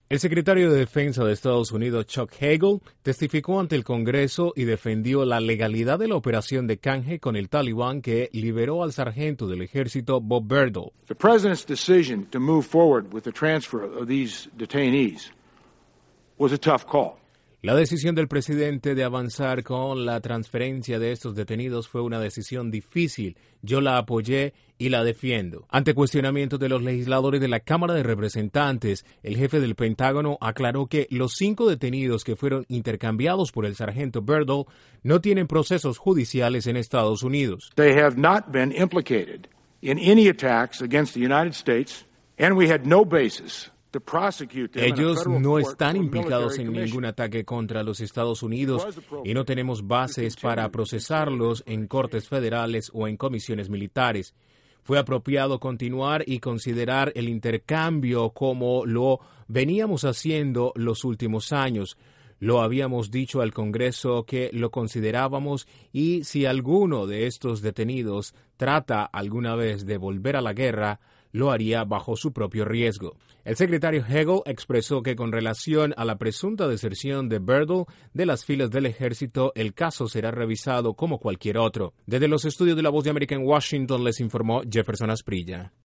El Secretario de Defensa de Estados Unidos defendió ante el Congreso el canje de sargento Bowe Bergdahl…Desde la Voz de América en Washington informa